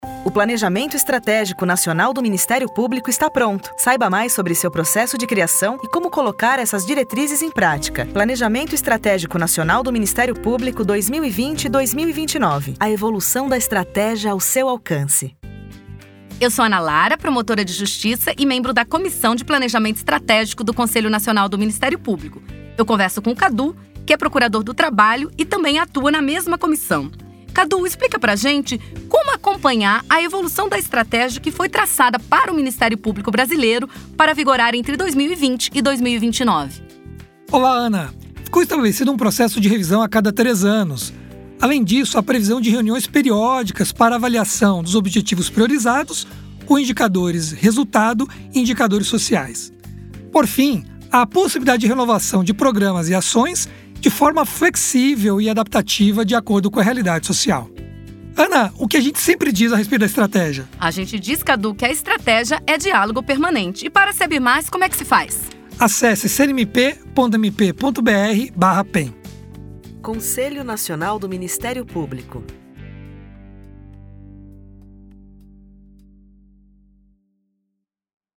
A iniciativa conta com cards para as redes sociais, gif, cartaz, e-mail marketing e podcasts com entrevistas com membros que participaram do projeto.